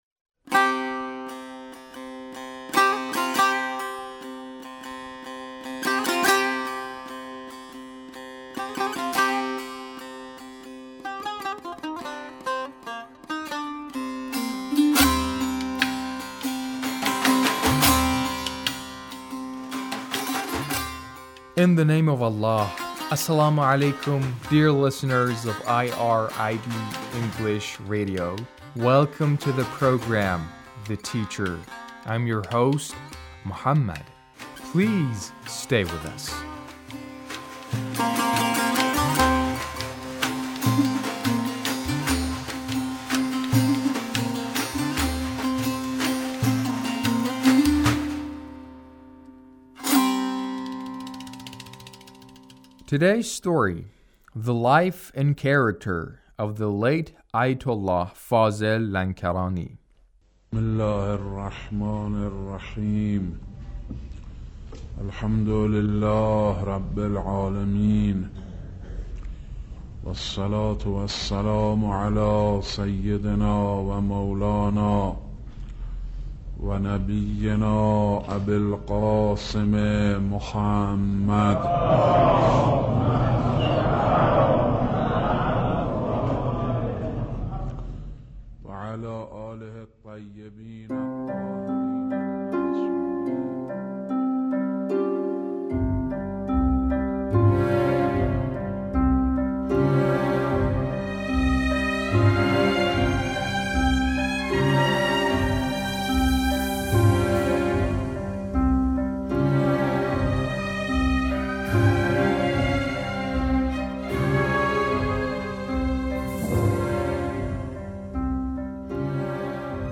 A radio documentary on the life of Ayatullah Fazel Lankarani